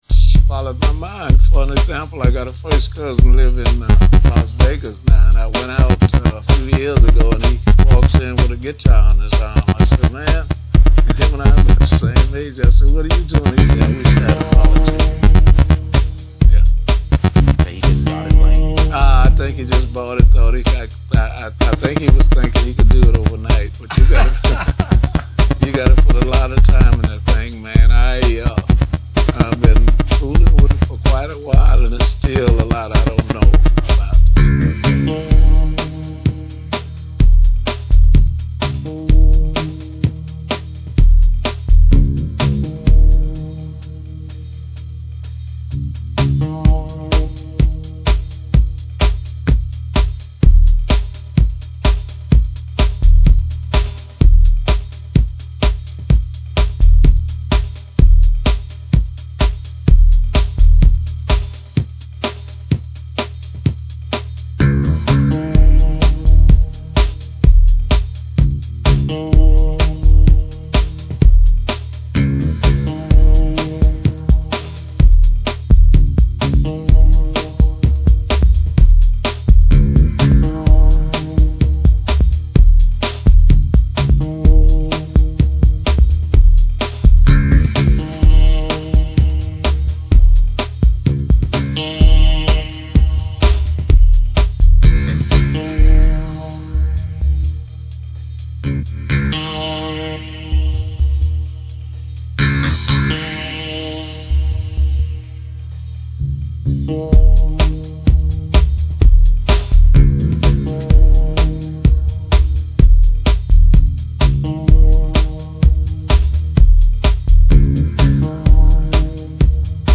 the vanguard of underground house music